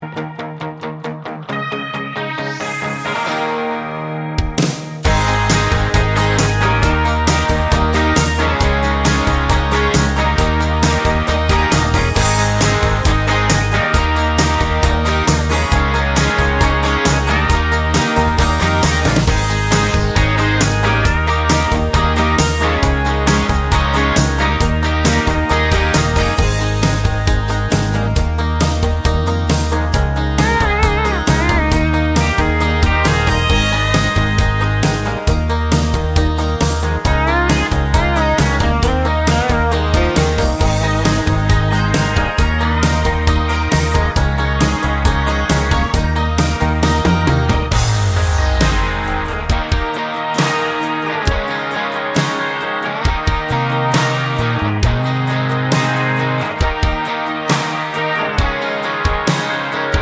no Backing Vocals Country (Male) 3:23 Buy £1.50